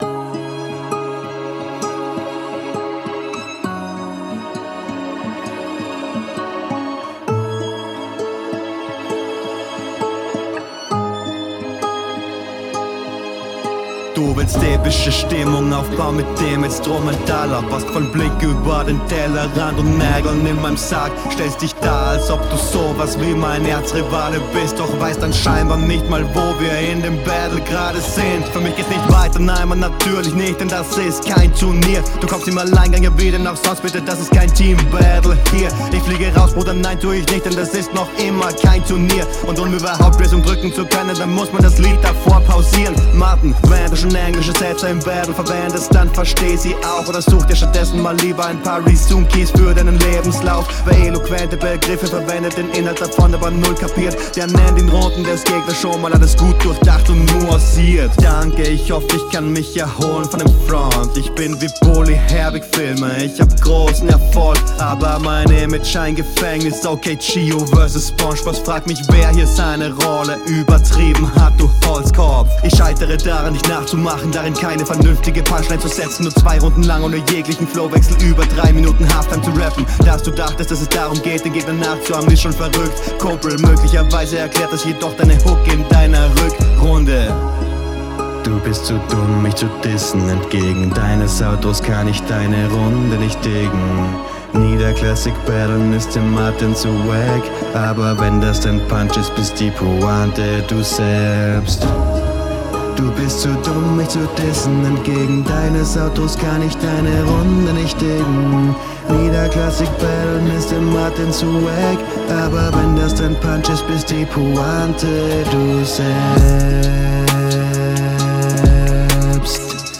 ''Instrumental'' war suuuuper weird ausgesprochen, das stört mich persönlich.
Einfach wieder spannender Flowansatz mit super viel Abwechslung, liebe sowas.